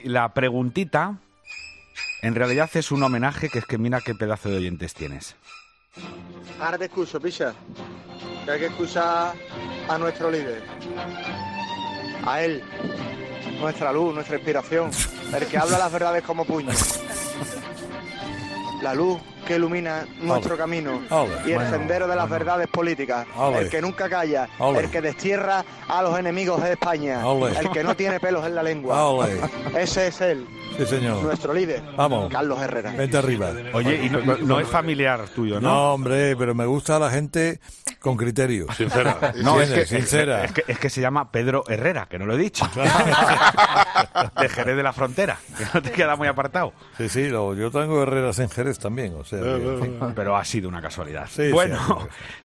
Un seguidor ha dejado en el contestador del programa un mensaje lleno de alabanzas hacia el comunicador